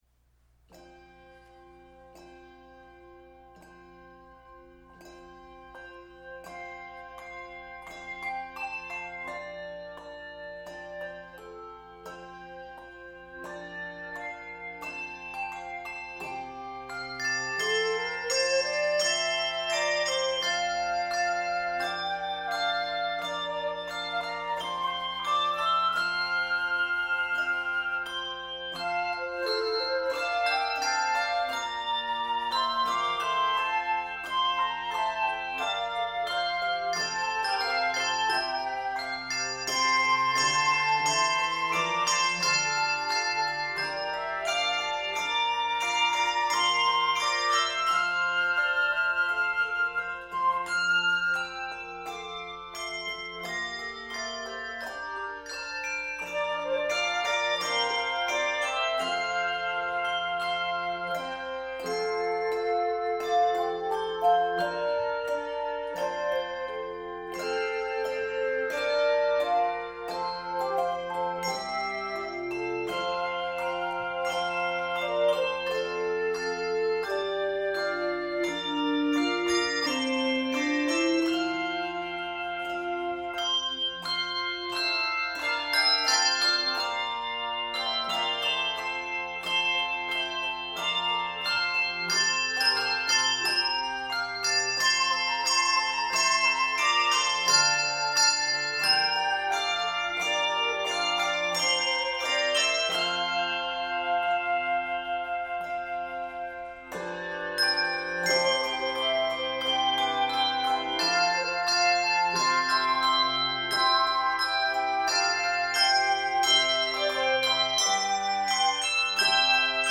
vibrant hymn setting
Keys of F Major and C Major.